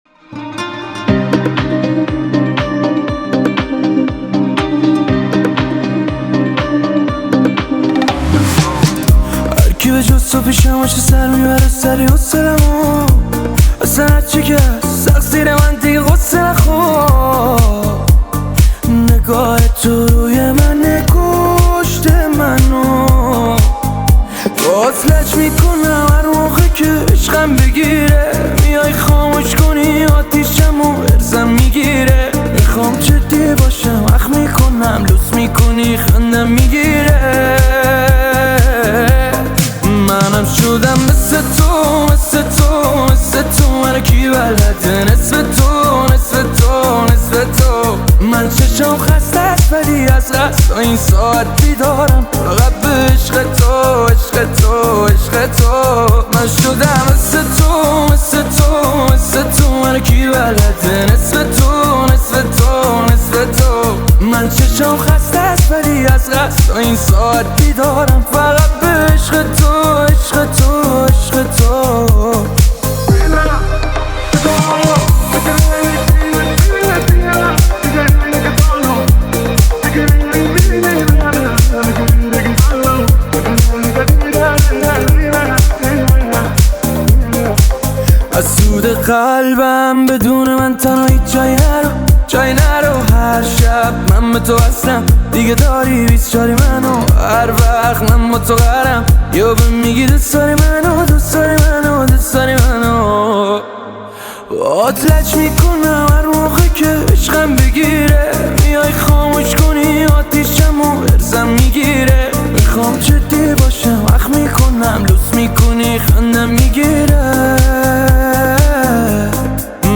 Live Performance Mix